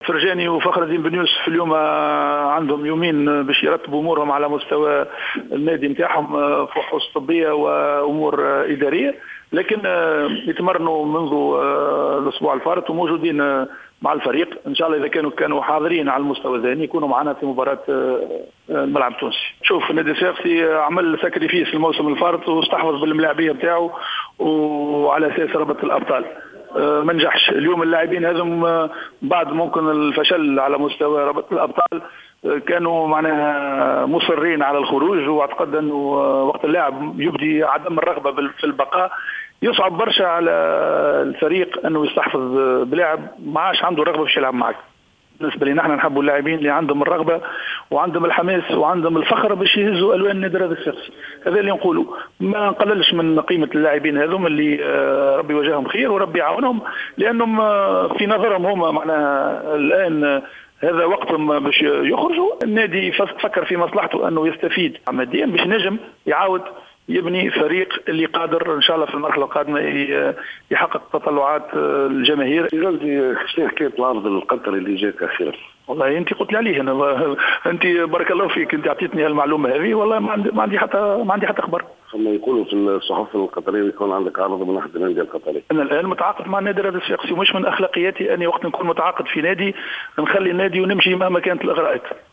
خلال ندوة صحفية عقدها مدرب النادي الصفاقسي غازي الغرايري صباح اليوم الاثنين 22 ديسمبر للحديث حول اخر استعدادات فريقه لمباراة الجولة 14 للرابطة المحترفة الأولى لكرة القدم و التي تجمعه بفريق نجم المتلوي يوم الاربعاء تحدث عن مغادرة كل من الفرجاني ساسي و فخر الدين بن يوسف الفريق مبينا أن النادي الصفاقسي يريد لاعبين لديهم رغبة و حماس في تقمص زي النادي .